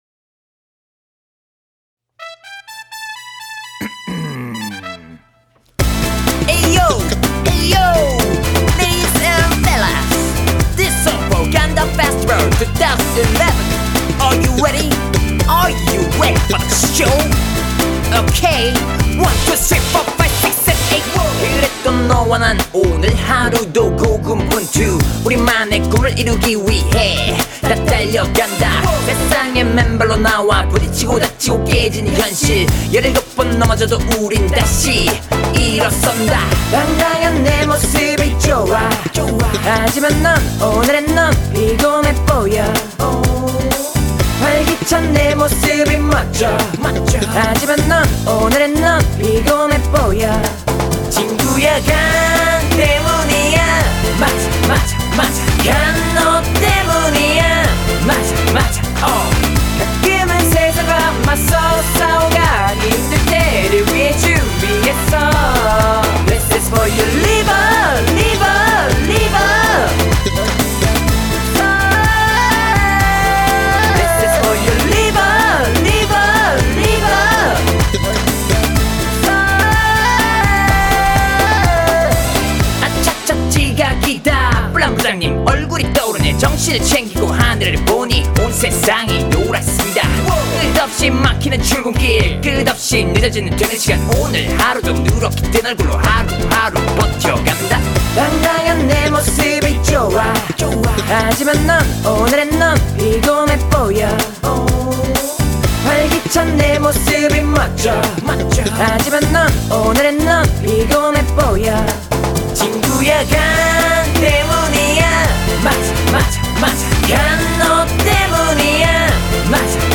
멜로디가 자꾸 들어도 질리지않고 좋네요 추천
리듬이 귀에 쏙 쏙 들어오고 좋네요!!